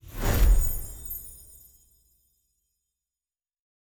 Positive Effect 1_4.wav